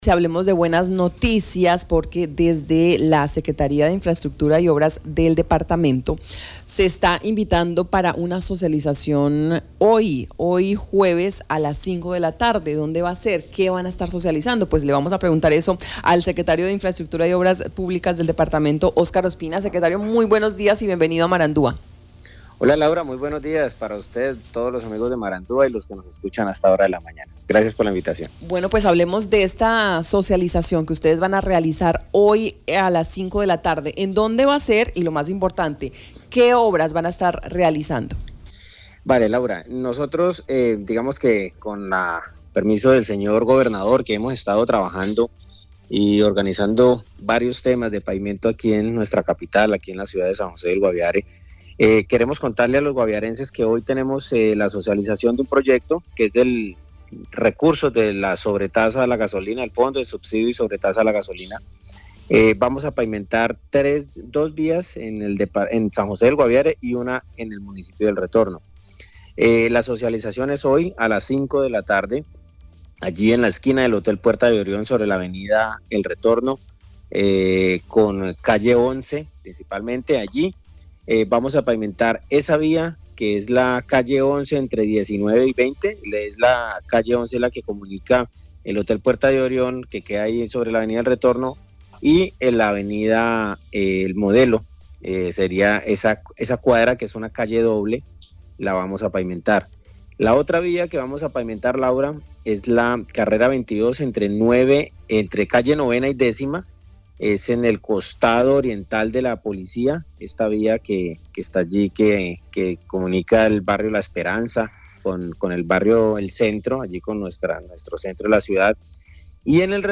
Marandua Noticias conversó con Óscar Ospina, Secretario de Infraestructura, quien se refirió a la socialización de dos proyectos de pavimentación en San José del Guaviare y uno en el municipio de El Retorno.